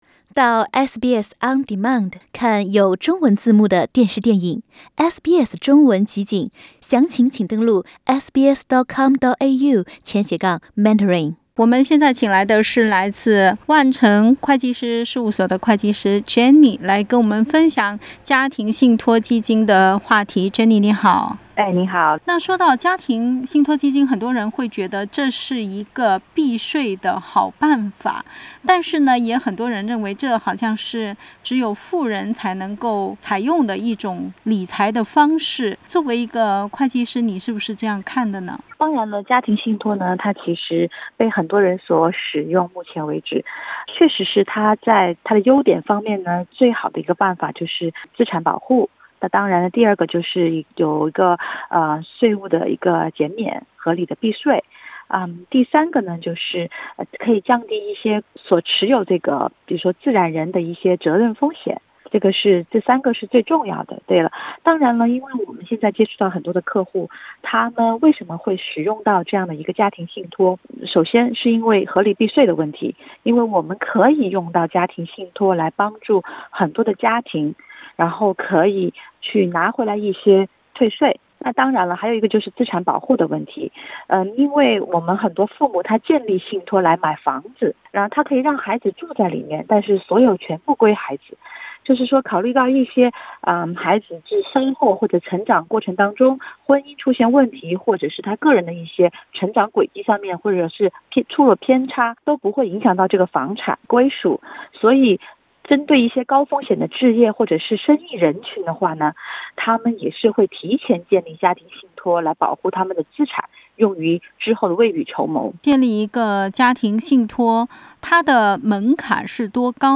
（本节目为嘉宾观点，仅供参考。）